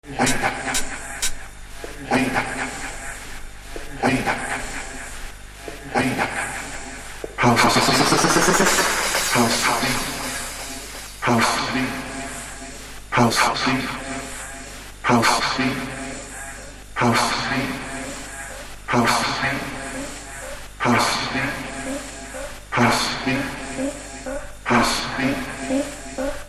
Fierce modern club tools with a bit of jack in it
Techno